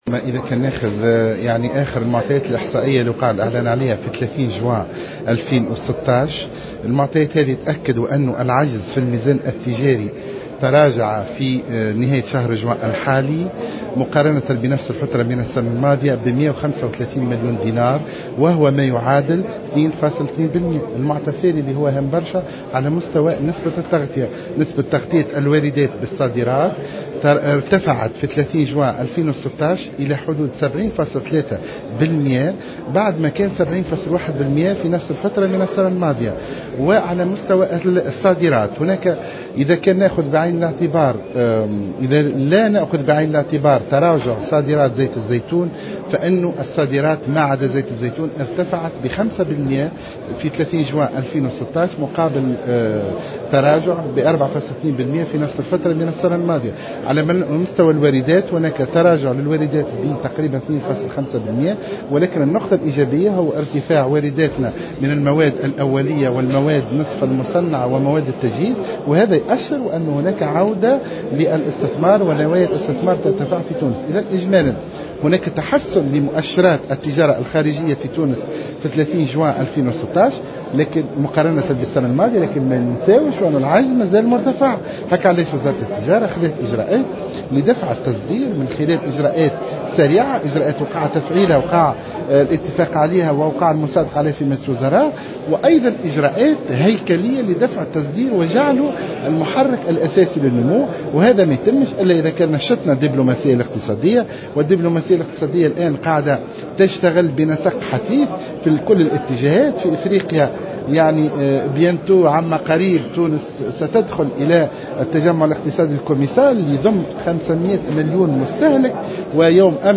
وأضاف محسن حسن، في تصريح للجوهرة أف أم لدى إشرافه اليوم الأربعاء على يوم اقتصادي ولقاءات مباشرة مع رؤساء البعثات الدبلوماسية والقنصلية التونسيين المعتمدين بالخارج، أن المؤشرات تبين ارتفاع نسبة تغطية الواردات بالصادرات في جوان 2016 لتبلغ 70.3 % مقابل 70.1 % خلال الفترة ذاتها من السنة الماضية، فيما ارتفعت قيمة الصادرات -دون أخذ صادرات زيت الزيتون بعين الاعتبار- بنسبة 5 % مقابل تراجع بنسبة 4.2 % خلال سنة 2015.